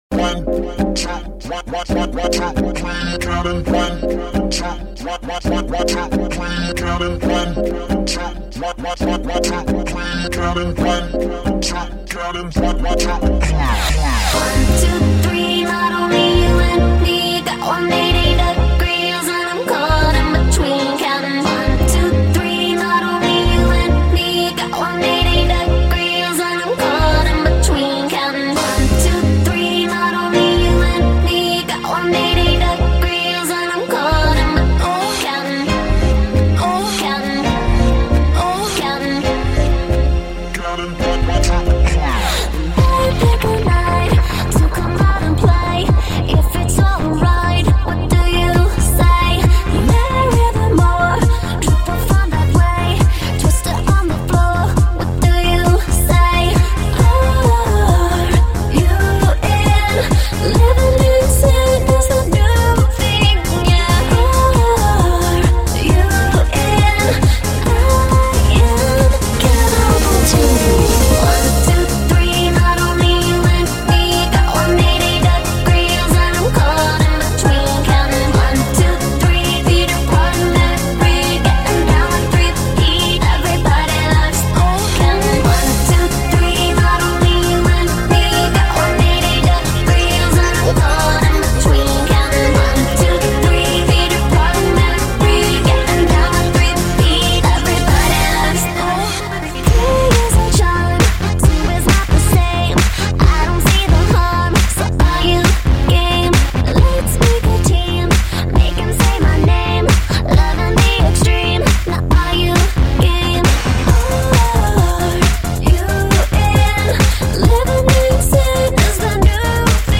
慢搖酒吧